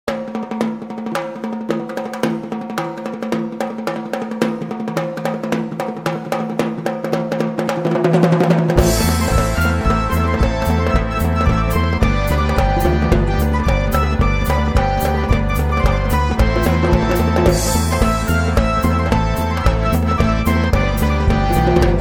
Лезгинка музыкальный жанр